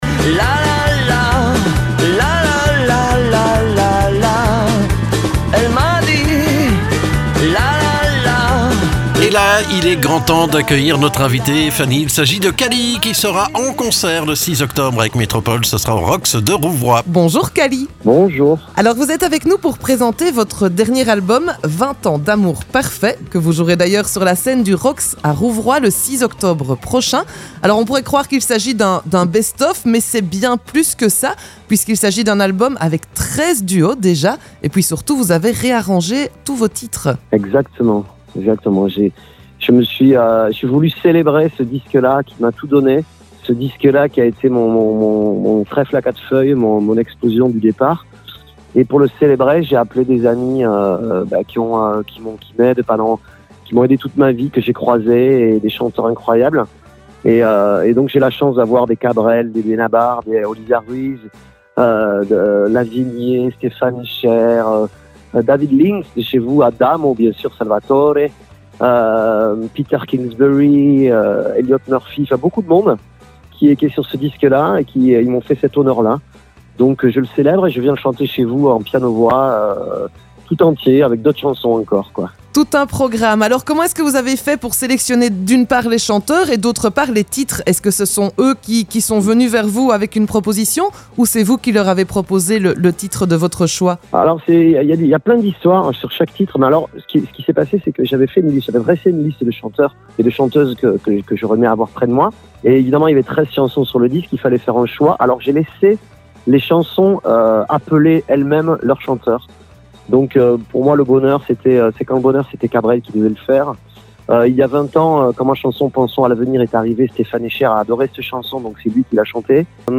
Il nous dévoile ce qui vous attend sur la scène du Rox le 6 octobre prochain.